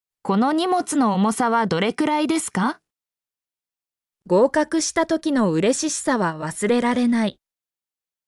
mp3-output-ttsfreedotcom-53_MCHqUclq.mp3